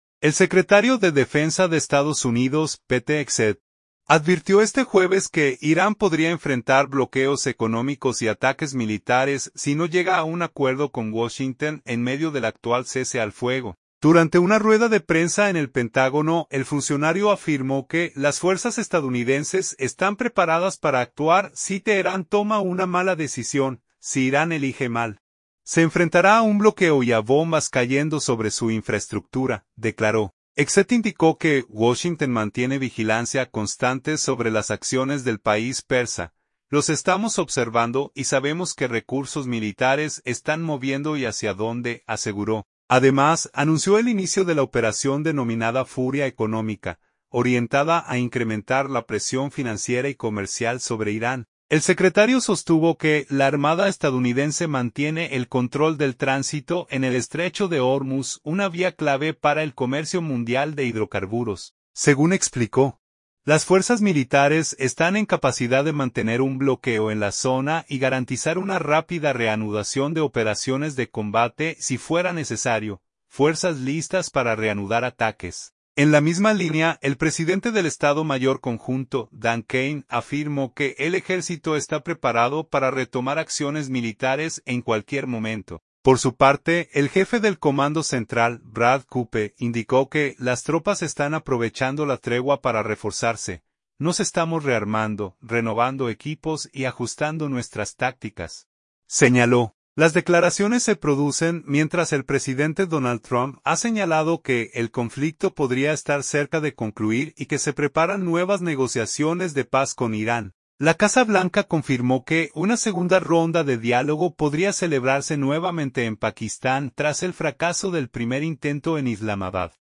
Durante una rueda de prensa en el Pentágono, el funcionario afirmó que las fuerzas estadounidenses están preparadas para actuar si Teherán “toma una mala decisión”.